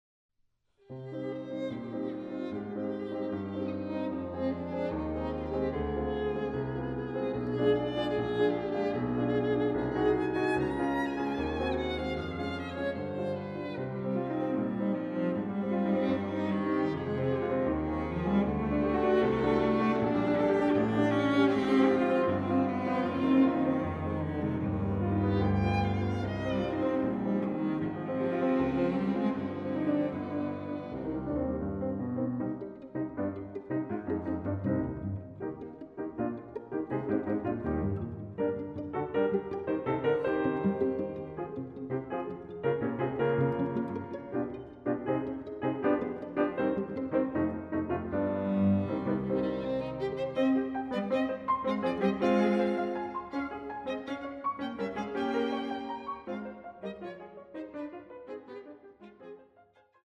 Petites pièces pour piano, violon et violoncelle